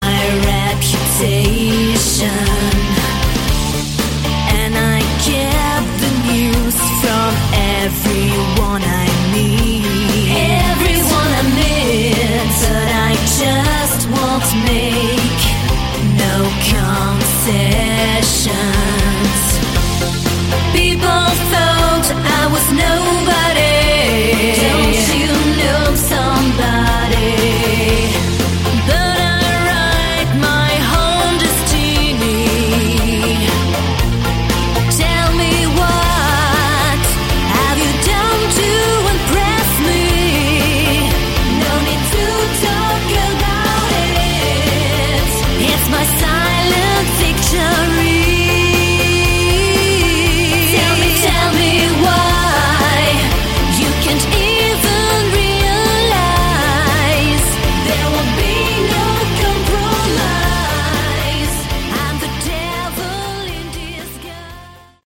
Category: Melodic Rock
lead and backing vocals